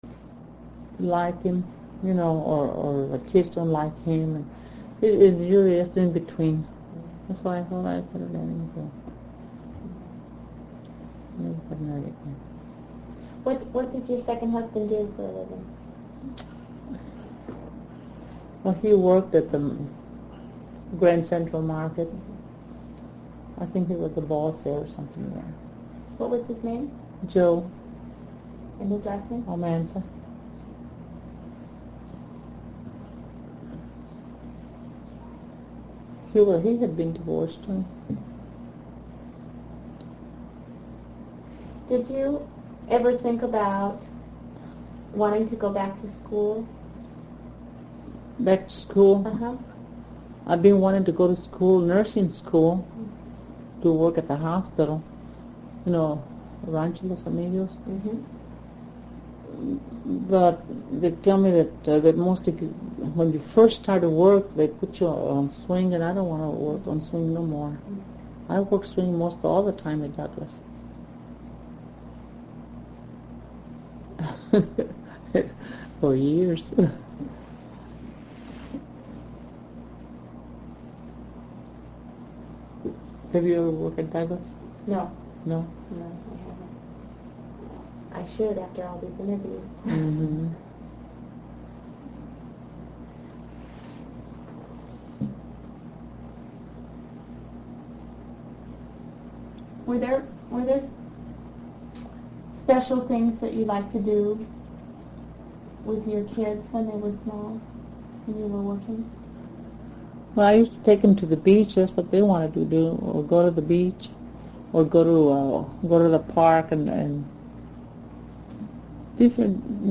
(audio interview #2 of 3)